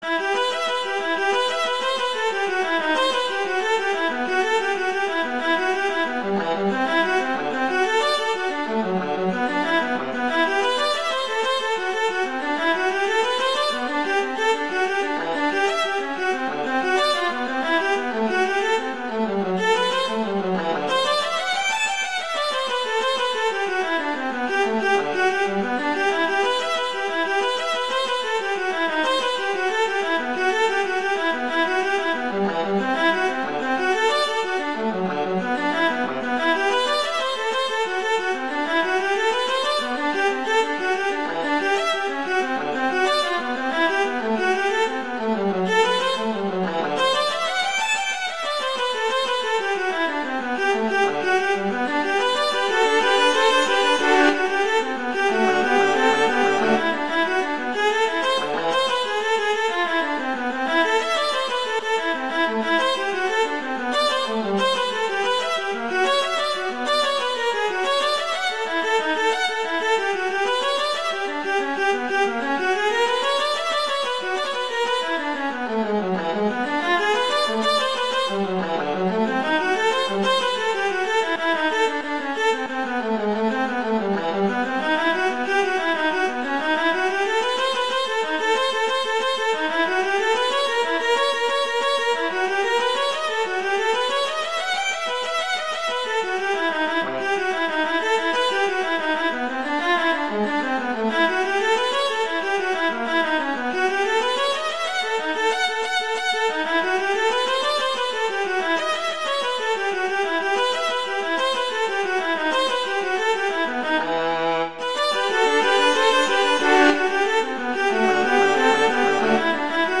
viola solo
classical